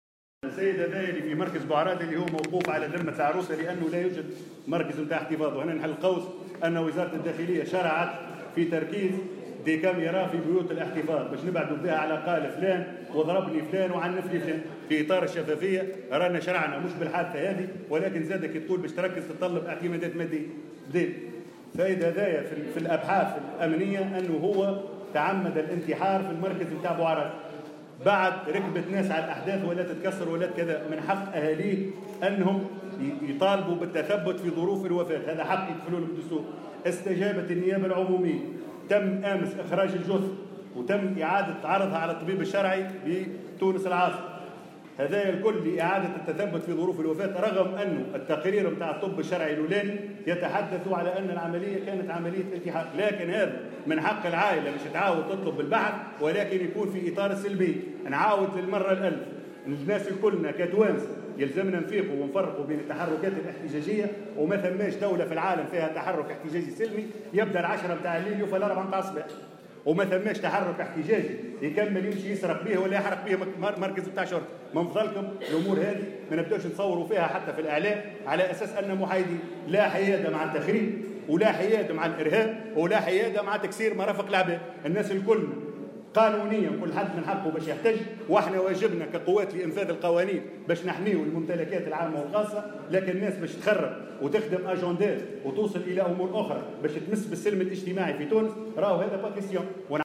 وأضاف في تصريح لمراسل "الجوهرة أف أم" خلال ندوة صحفية عقدتها الوزارة اليوم أنه تم إعادة عرض الجثة على الطبيب الشرعي، على الرغم من أن الأبحاث الامنية أكدت أنه أقدم على الانتحار.